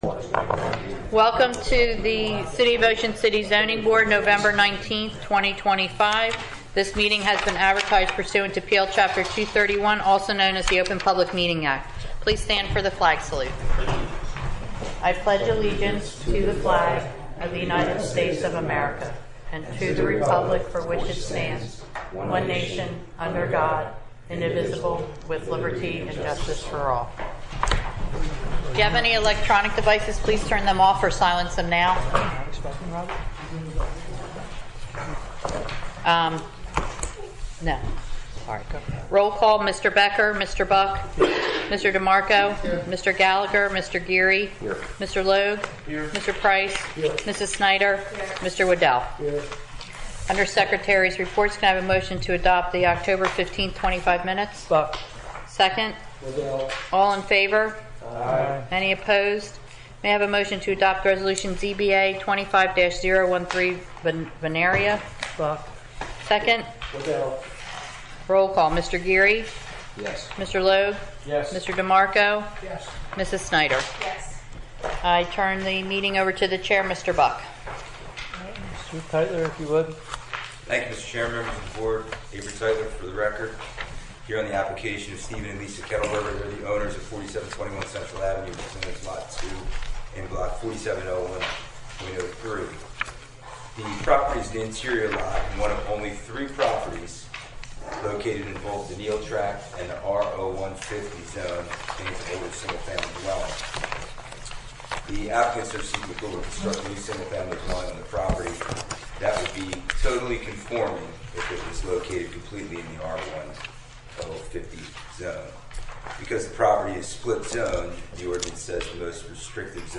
Welcome to Ocean City, New Jersey, America's Greatest Family Resort - Zoning Board Meeting